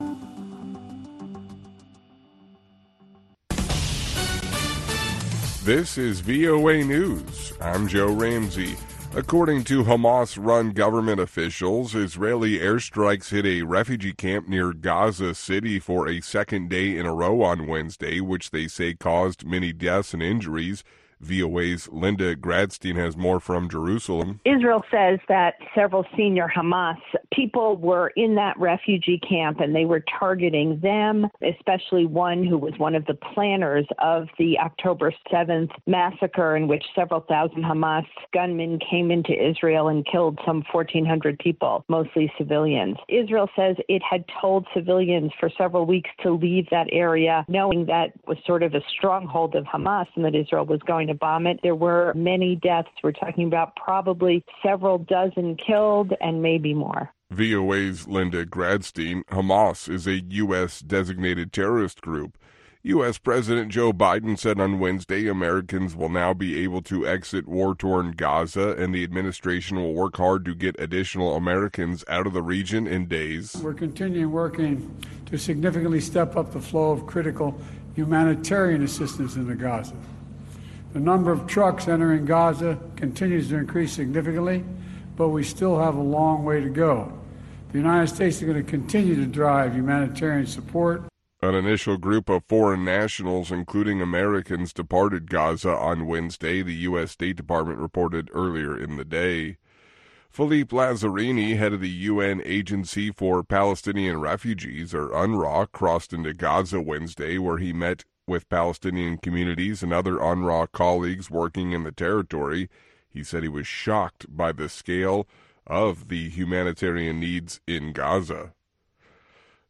Two Minute Newscast